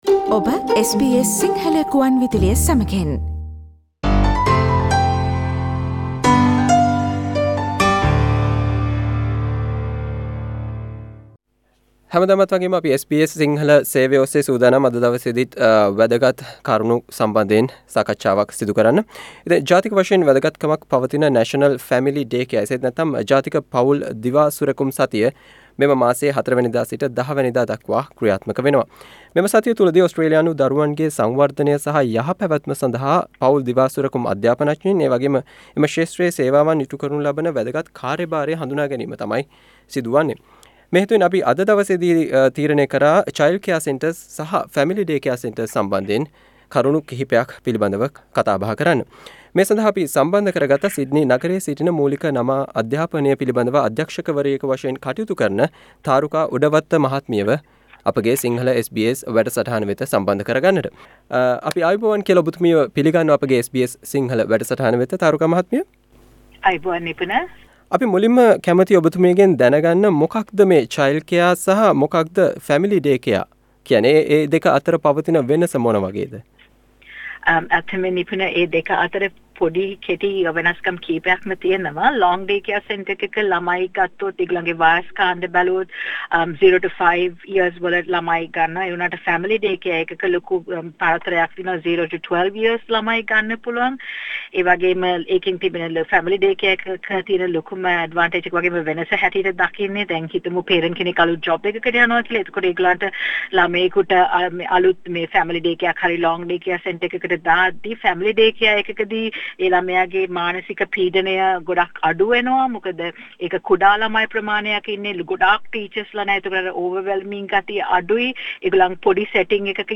SBS Sinhalese radio discussion